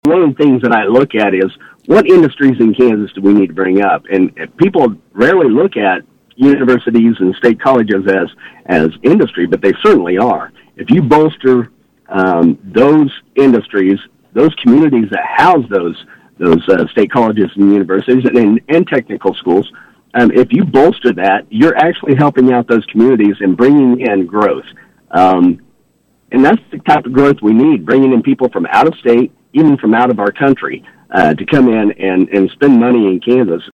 On KVOE’s Morning Show on Thursday, Smith looked at the $2 billion cash balance already in hand with the possibility of that growing by the end of the current fiscal year in July.